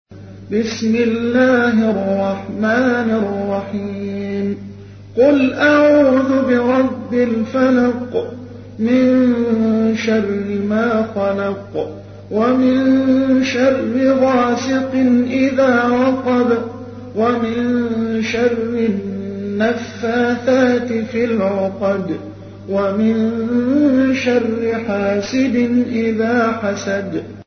تحميل : 113. سورة الفلق / القارئ محمد حسان / القرآن الكريم / موقع يا حسين